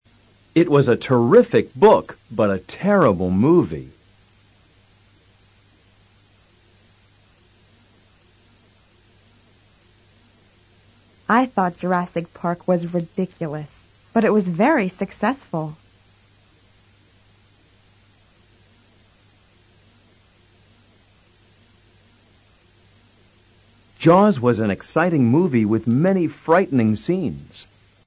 Escucha en este audio la acentuación de estas oraciones con adjetivos.